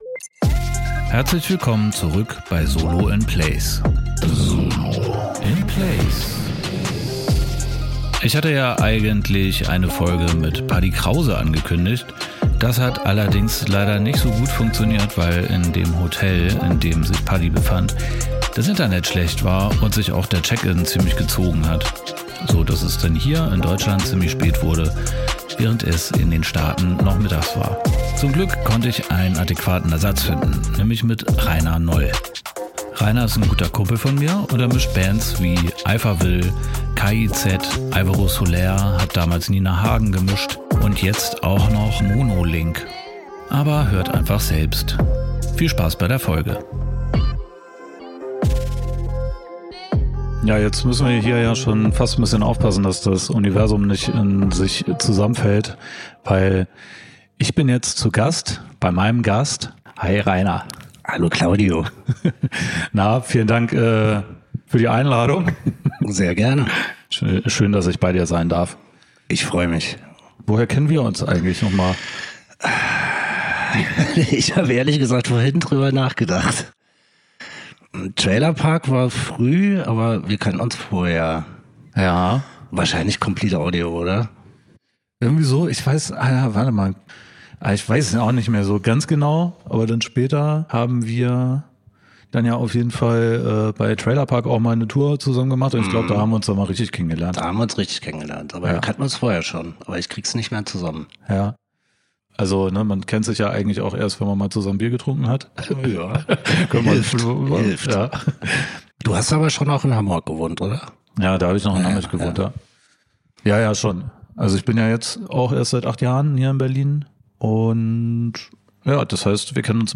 Diese Folge ist weniger Nerd-Talk und mehr Haltungsgespräch – über Gelassenheit, Erfahrung, Weltneugier und die Frage, wie man nach Jahrzehnten im Business immer noch Freude am Job hat.